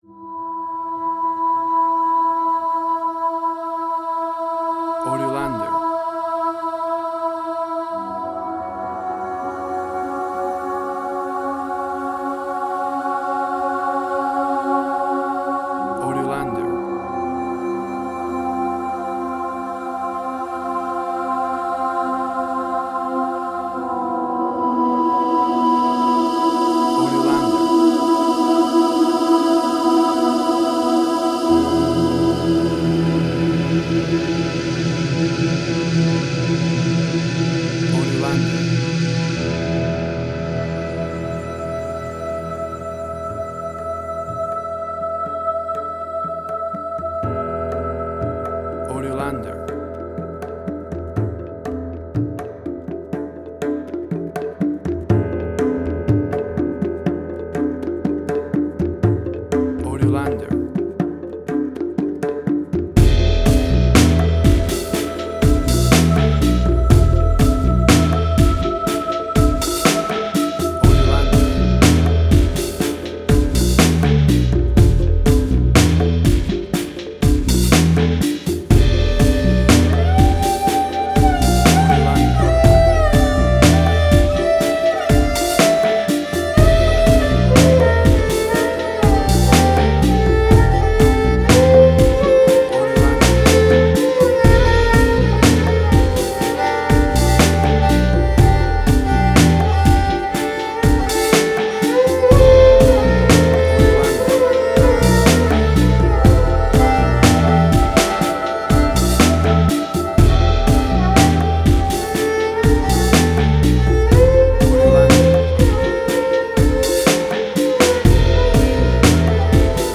Indian Fusion
Tempo (BPM): 61